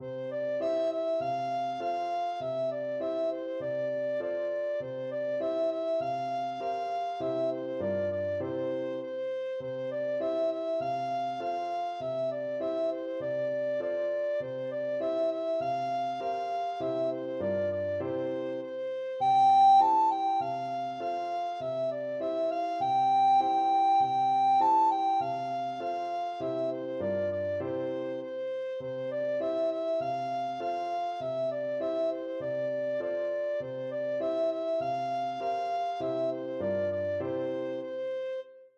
Free Sheet music for Soprano (Descant) Recorder
4/4 (View more 4/4 Music)
C6-A6
Classical (View more Classical Recorder Music)